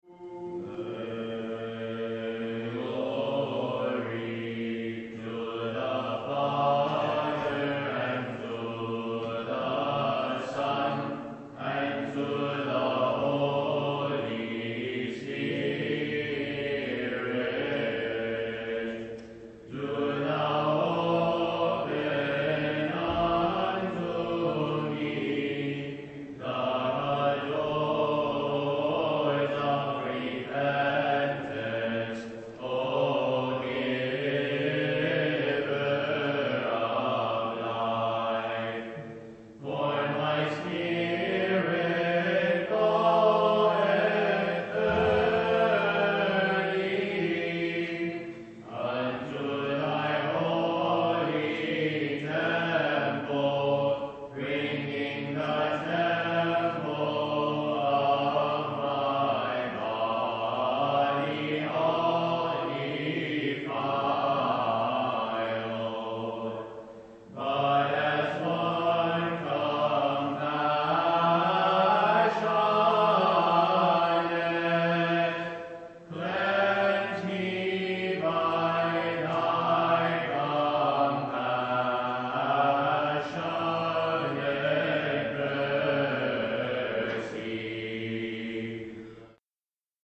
Recordings of our Byzantine Choir
Live Recordings from Services